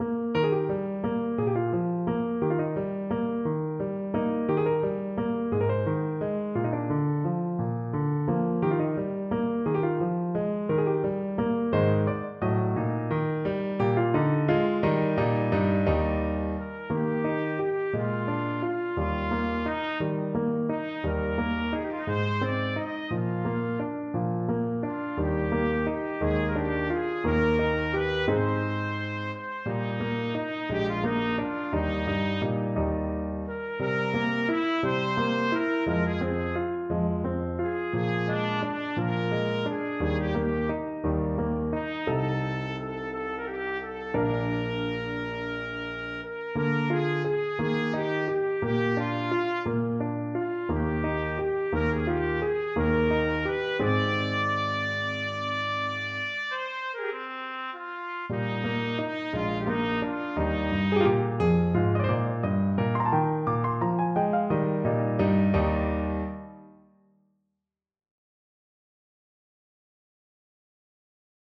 6/8 (View more 6/8 Music)
C5-Eb6
Andantino .=58 (View more music marked Andantino)
Classical (View more Classical Trumpet Music)